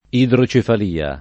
idrocefalia [ idro © efal & a ]